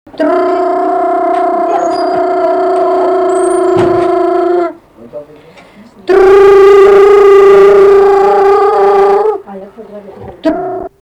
vokalinis
smulkieji žanrai